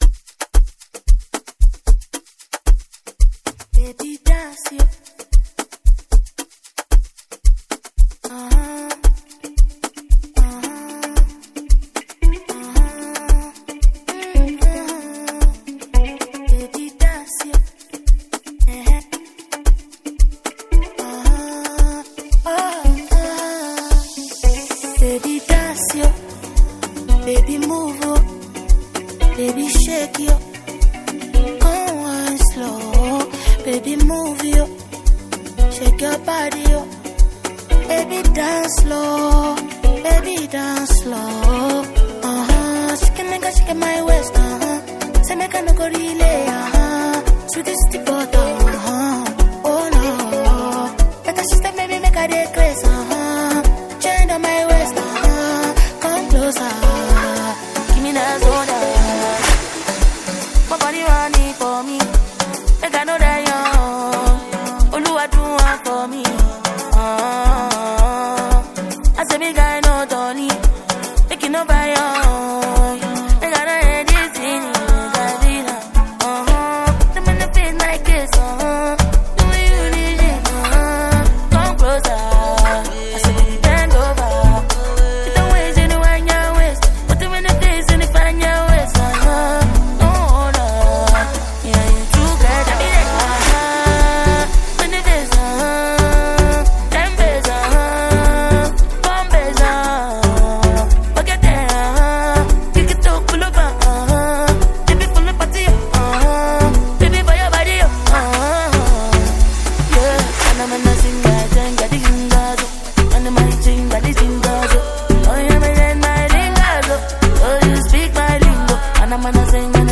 is an upbeat song